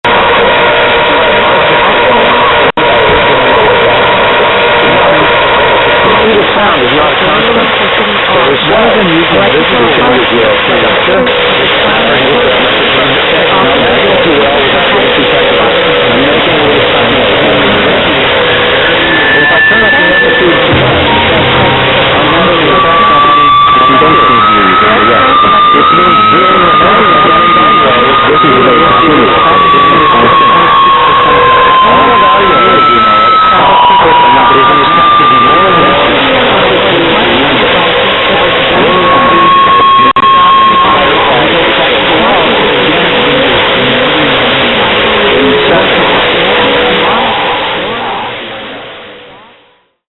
speak thinking in ear recording (jack and jill)